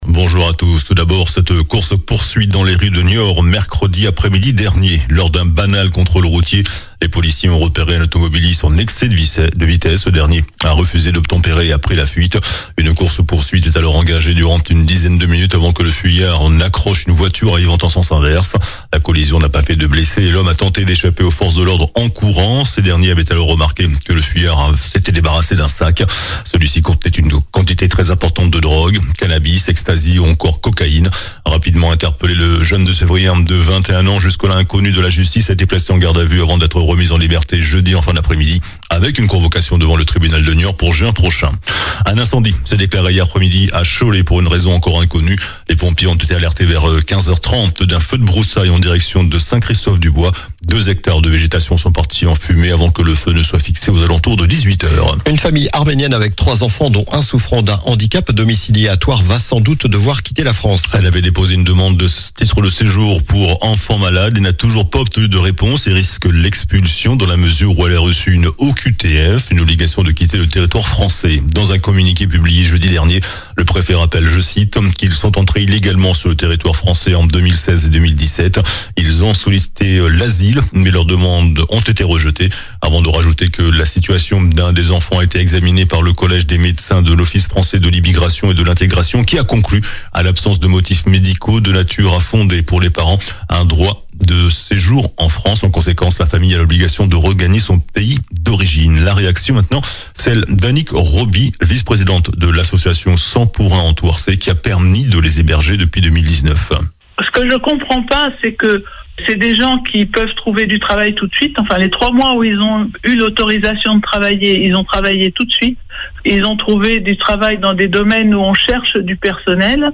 JOURNAL DU SAMEDI 23 AOÛT